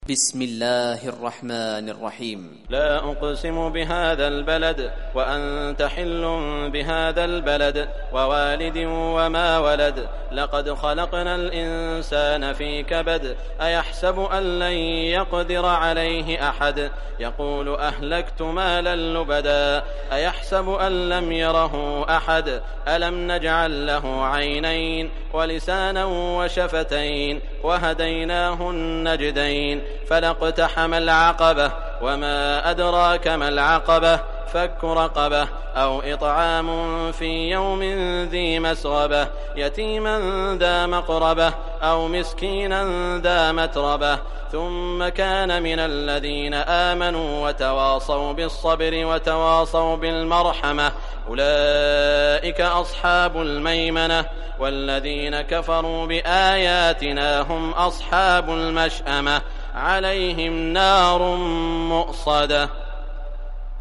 Surah Al Balad Recitation by Sheikh Shuraim
Surah al Balad, listen or play online mp3 tilawat / recitation in Arabic in the beautiful voice of Sheikh Saud al Shuraim.